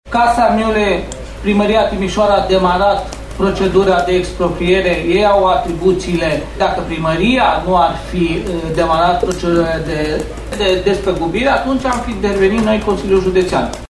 Președintele CJ Timis, Călin Dobra.